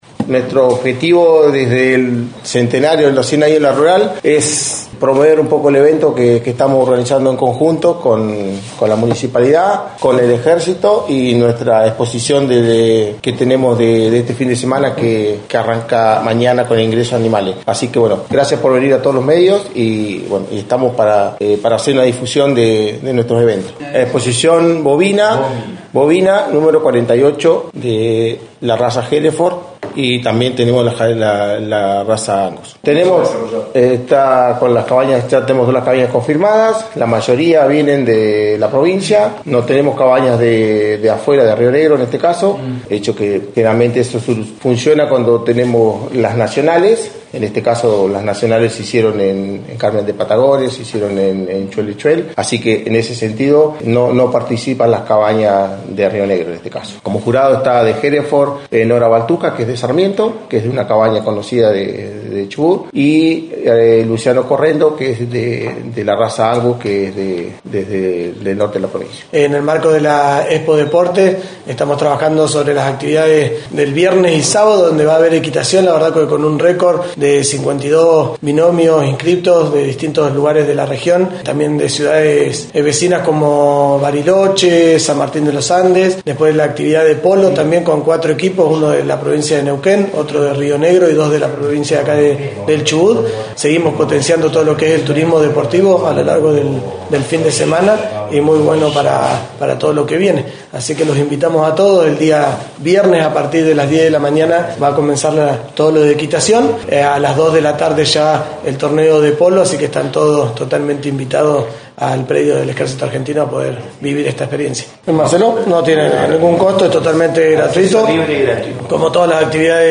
Del 9 al 12 de octubre se llevara a cabo en la Sociedad Rural de Esquel, la 48° Exposición Bovina. En esta oportunidad se hizo una conferencia de prensa para presentar las actividades ecuestres como la equitación y el polo.